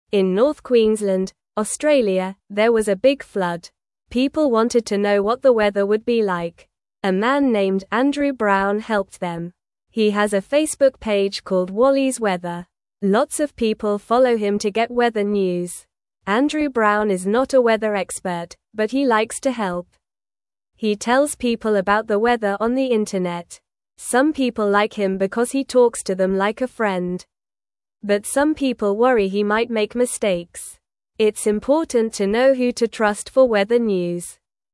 Normal
English-Newsroom-Beginner-NORMAL-Reading-Helping-Friends-with-Weather-News-in-Australia.mp3